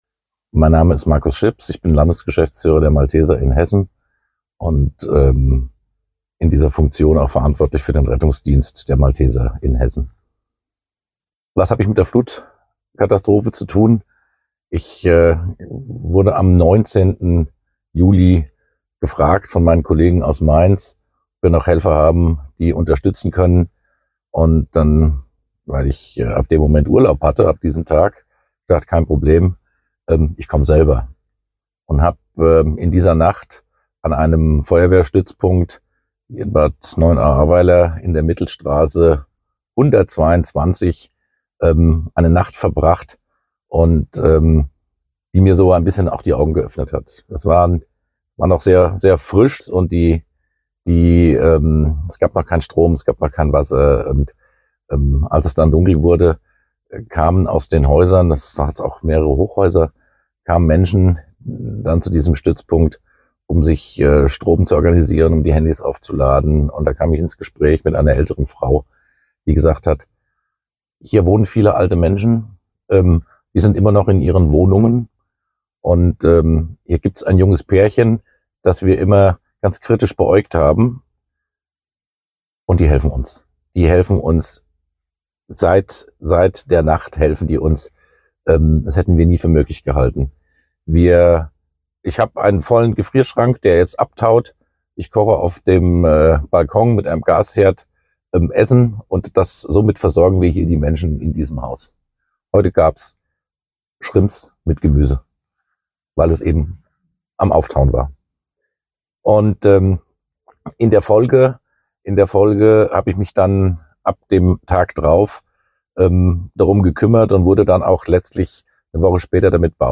Sechs von ihnen teilen ihre persönlichen Eindrücke mit uns, sprechen ungefiltert und offen von ihren Erfahrungen, die sie bis heute begleiten.